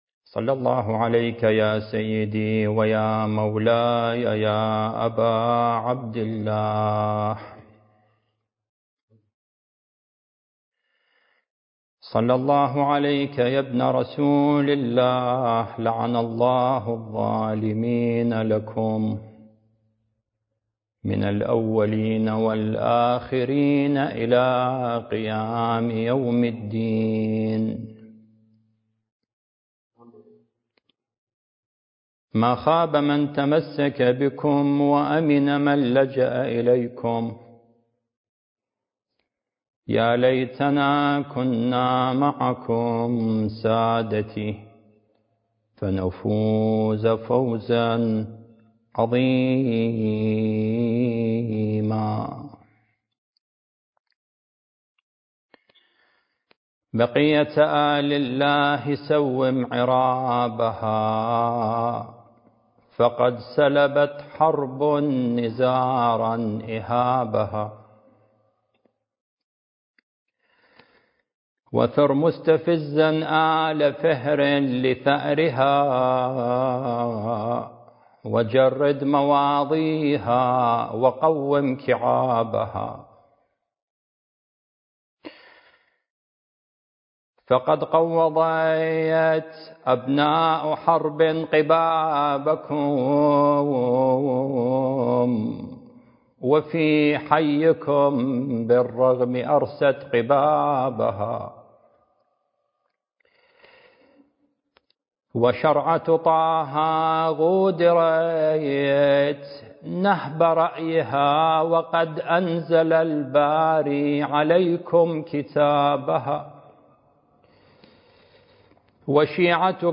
المكان: الحسينية المهدية - مركز الدراسات التخصصية في الإمام المهدي (عجّل الله فرجه) - النجف الأشرف